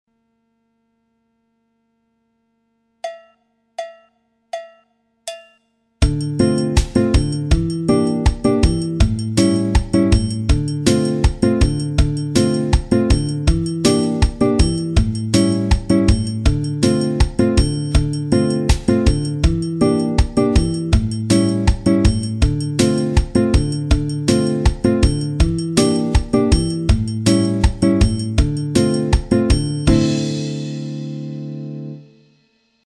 Le xote 1 à la guitare Une des différentes figures de guitare qui peuvent illustrer le Xote et peuvent se mélanger selon les chansons.